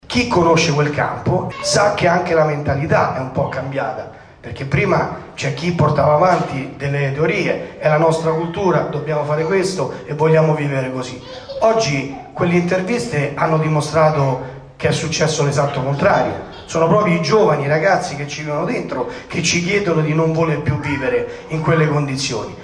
A Roma nel Municipio 6 è stato presentato il progetto di superamento del campo rom di via Salone. Ascoltiamo Nicola Franco, presidente del sesto Municipio.